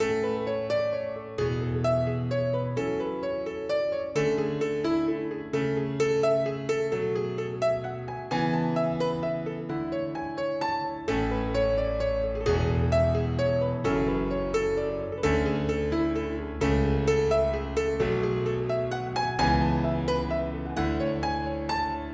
原声钢琴130bpm的Amajor混音版
Tag: 130 bpm Pop Loops Piano Loops 3.73 MB wav Key : A